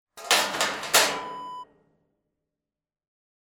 Water Tank Lid Close Wav Sound Effect #3
Description: The sound of closing the lid of a water tank
Properties: 48.000 kHz 24-bit Stereo
A beep sound is embedded in the audio preview file but it is not present in the high resolution downloadable wav file.
Keywords: water, tank, lid, metal, cover, container, storage, wood burning, stove, sauna, hot, heater, door, close, closing
water-tank-lid-close-preview-3.mp3